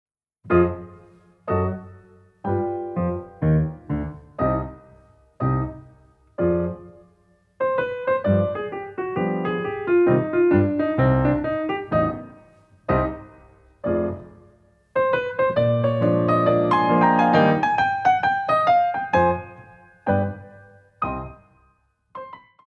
Compositions for Ballet Class
Dégagés à terre